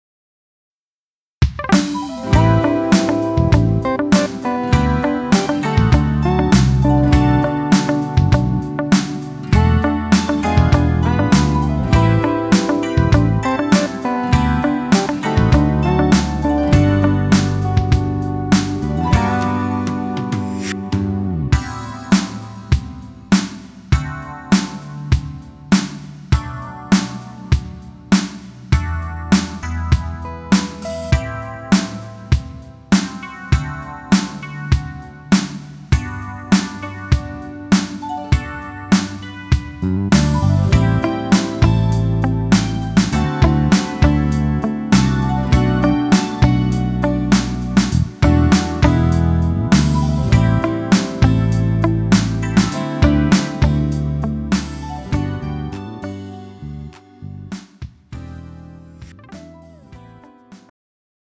음정 -1키 3:05
장르 가요 구분 Pro MR